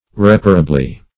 reparably - definition of reparably - synonyms, pronunciation, spelling from Free Dictionary Search Result for " reparably" : The Collaborative International Dictionary of English v.0.48: Reparably \Rep"a*ra*bly\, adv. In a reparable manner.